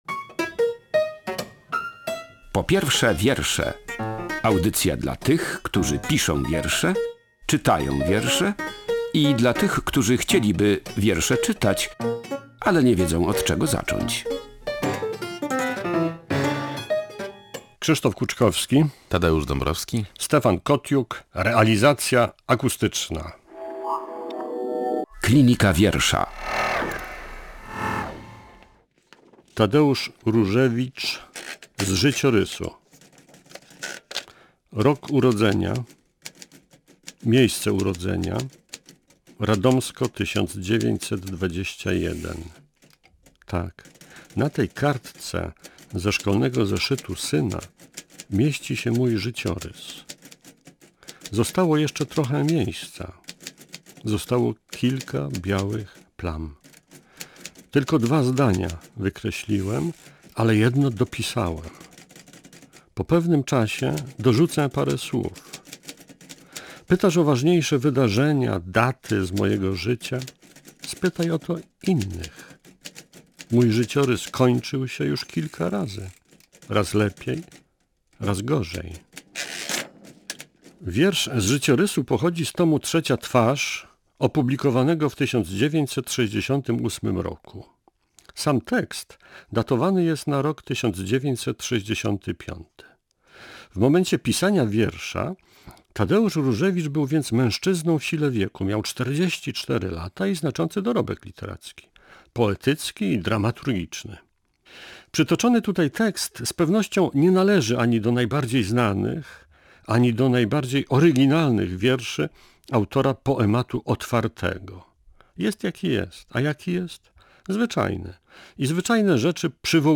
Druga część rozmowy z niemiecką pisarką i poetką Hertą Müller, laureatką literackiej Nagrody Nobla, znaną polskim czytelnikom z książek: "Sercątko",